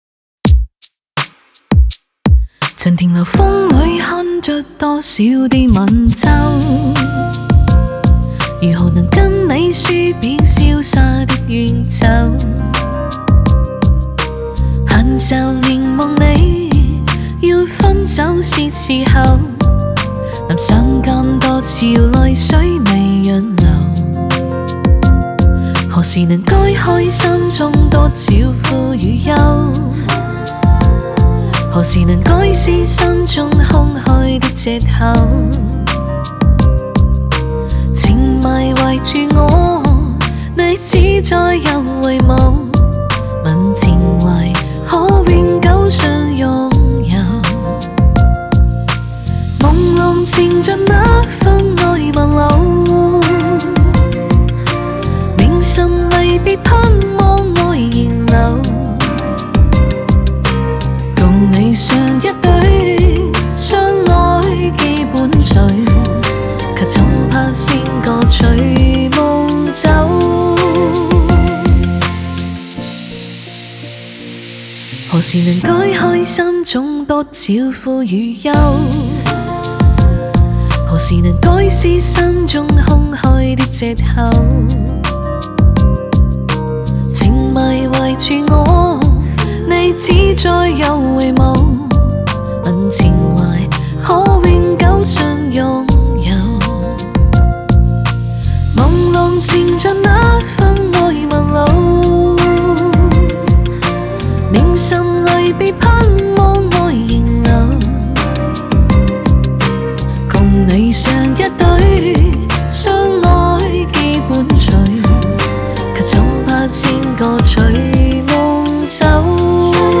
Ps：在线试听为压缩音质节选